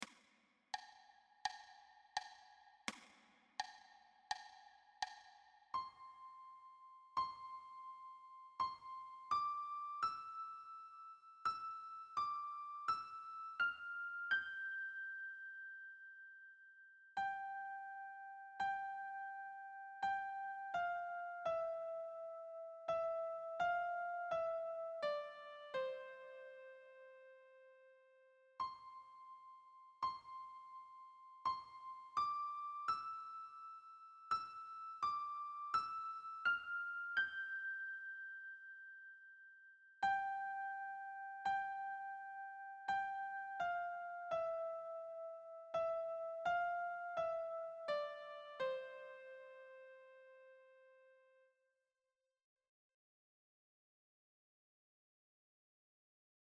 Lehrerbegleitung